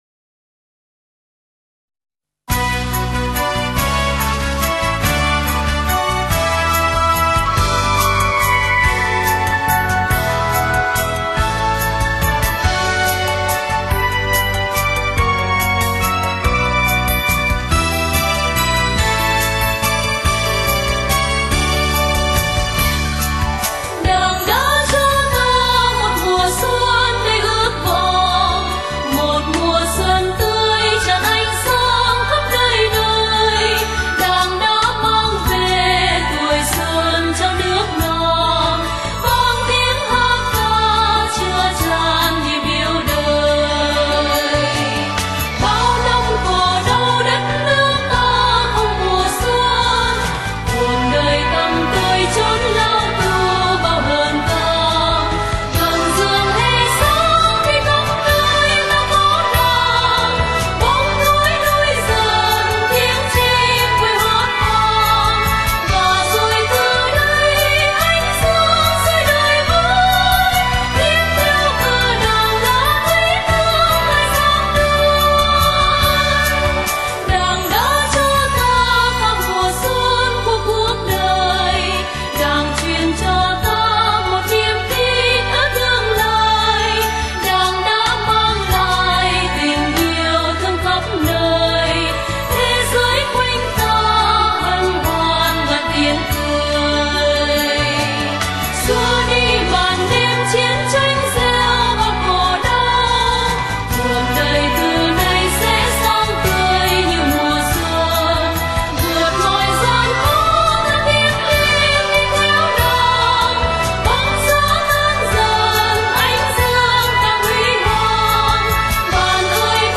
Sách nói | Đảng đã cho ta mùa xuân